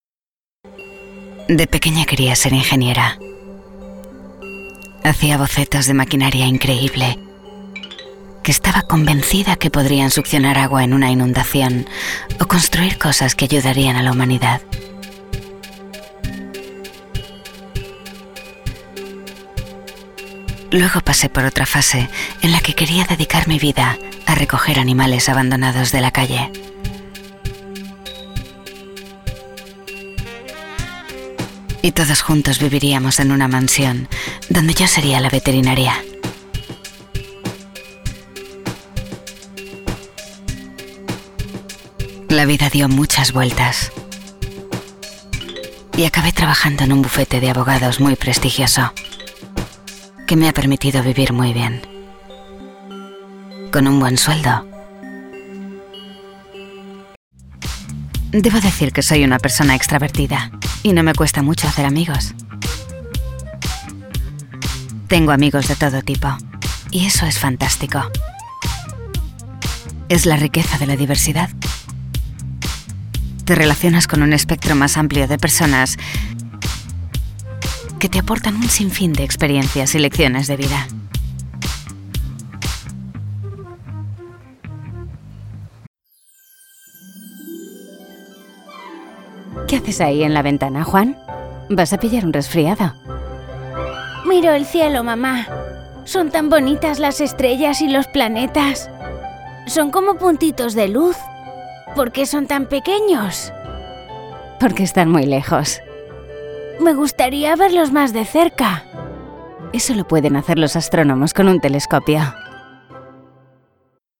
My voice has often been described as being versatile, intelligent and sincere, I record Tv commercials and Corporate presentations . I work in Spanish Castilian (with a genuine accent from Spain, not to be confused with Latin American accents) and English as a 2 language I speak fluent English as a second language.
VOICE ACTOR DEMOS
0420DOBLAJE_NO_ANUNCIOS.mp3